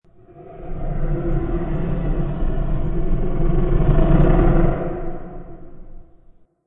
creepy_cave.wav